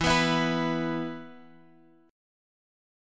E5 chord {x 7 9 9 x 7} chord
E-5th-E-x,7,9,9,x,7.m4a